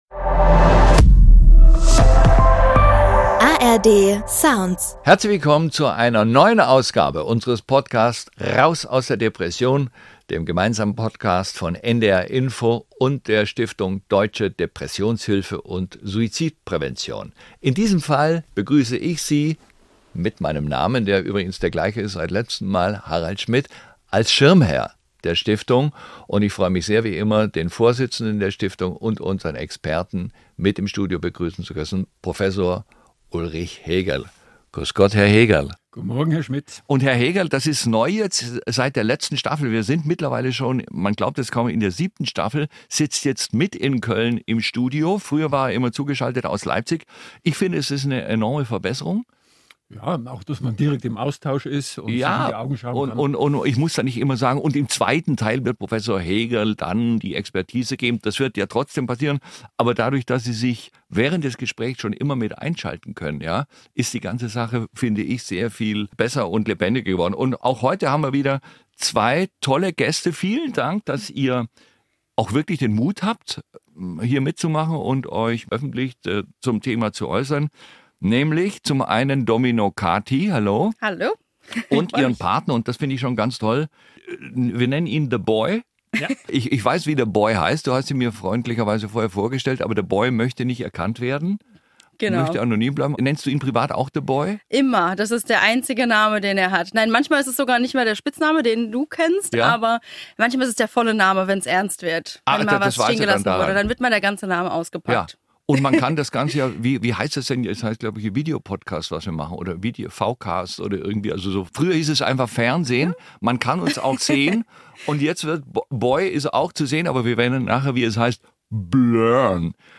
Es ist ein intensives Gespräch über Machtlosigkeit, Schuldgefühle und darüber, wie Akzeptanz und Zeit dabei helfen können, Schritt für Schritt zurück ins Leben zu finden.